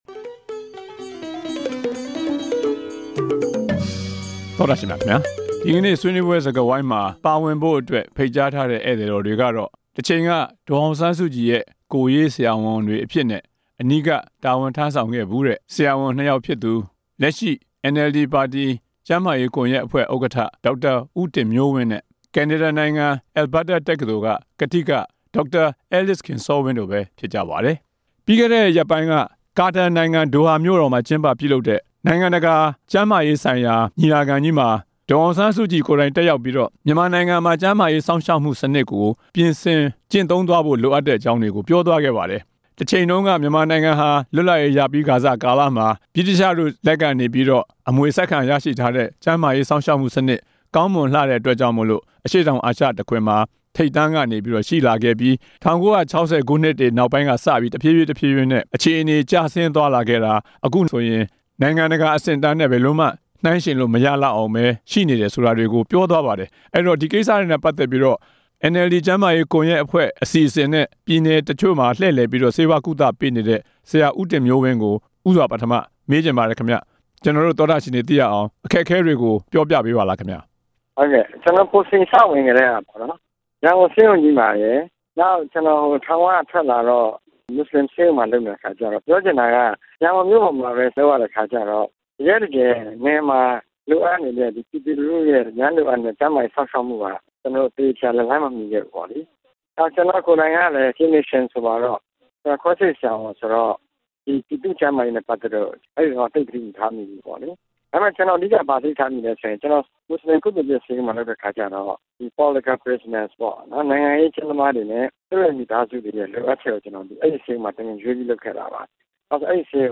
မြန်မာနိုင်ငံရဲ့ ကျန်းမာရေး စောင့်ရှောက်မှုစနစ် ယိုယွင်းမှု ဆွေးနွေးချက်